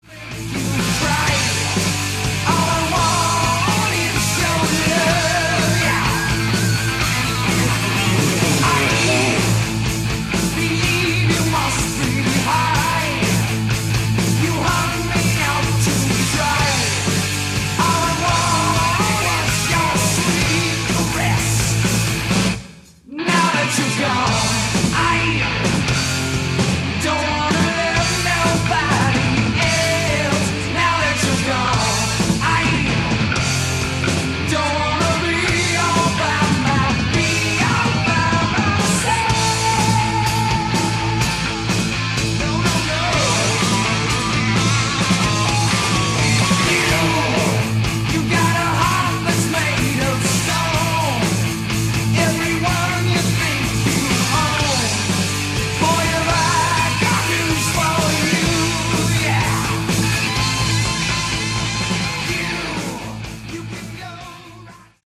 Category: Hard Rock
lead guitar, backing vocals
lead vocals
bass, backing vocals
drums, backing vocals